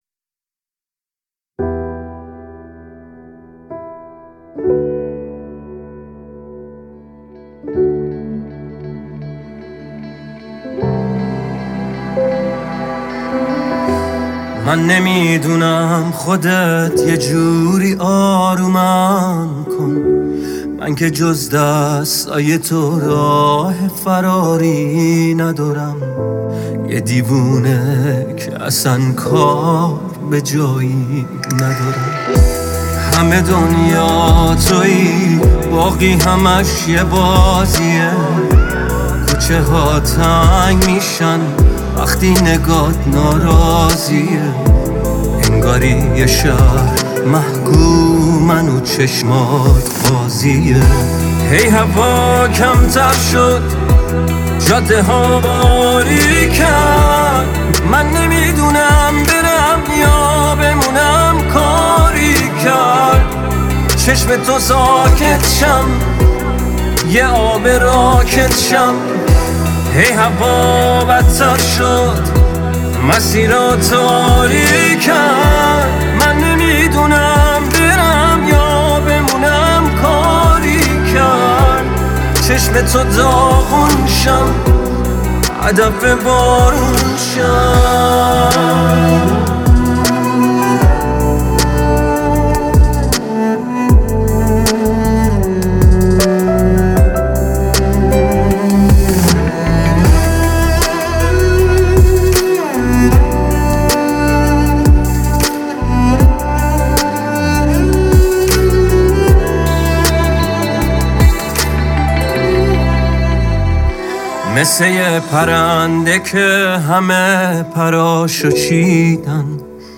با صدای گرم و گیرای خود